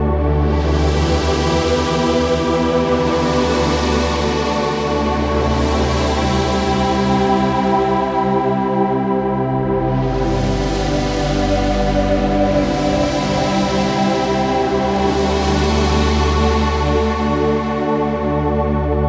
E D C G C D (对不起，101 BPM)
Tag: 101 bpm Chill Out Loops Pad Loops 3.21 MB wav Key : Unknown